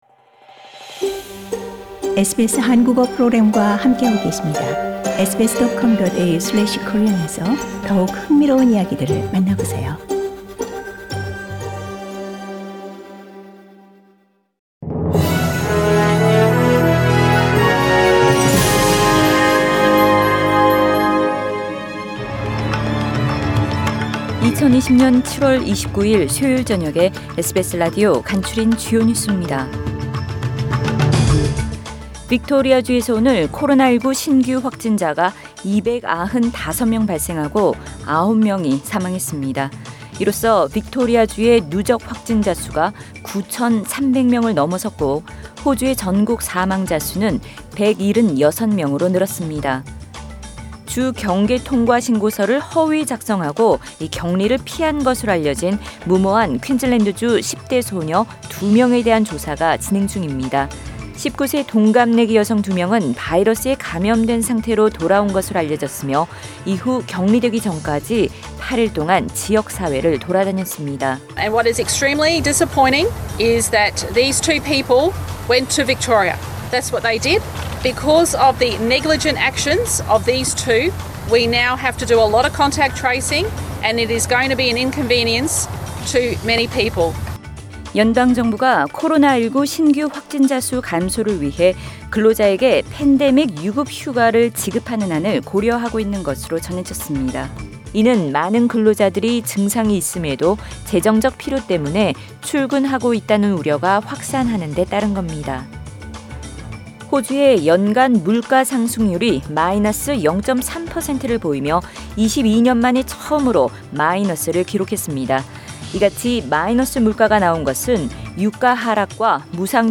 2020년 7월 29일 수요일 저녁의 SBS Radio 한국어 뉴스 간추린 주요 소식을 팟 캐스트를 통해 접하시기 바랍니다.